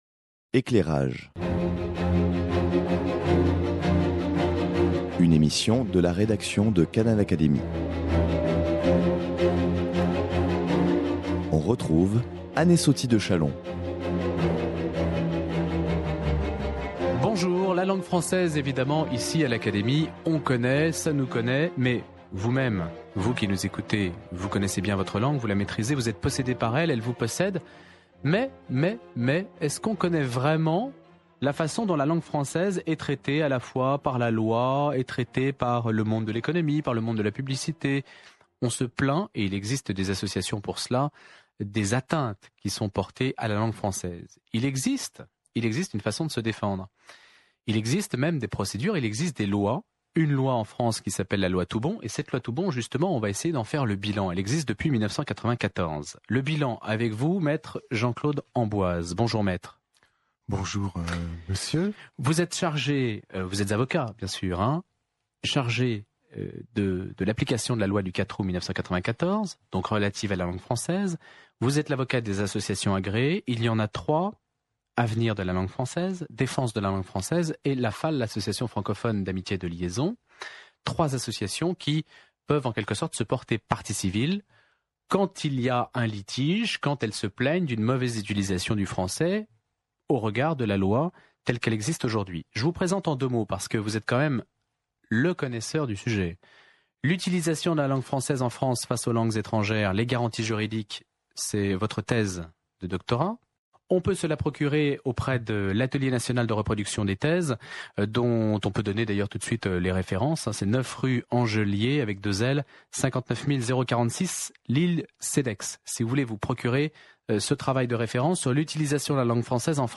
Douze ans après son entrée en vigueur, Canal Académie fait le point avec l'un des rares spécialistes qui connaît à fond toutes les implications juridiques et judiciaires lié à la « maltraitance » infligée à notre langue.